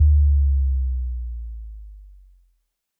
Bass Power Off.wav